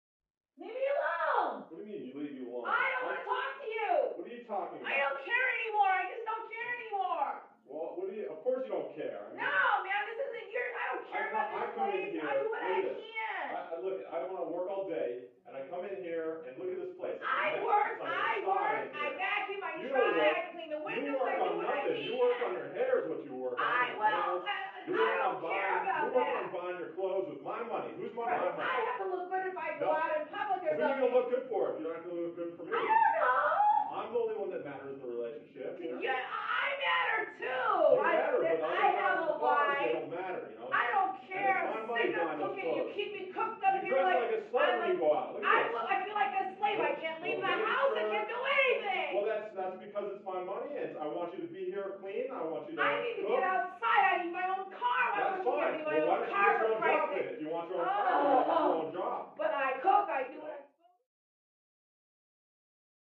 Argue; Couple Yelling At Each Other, From Next Door.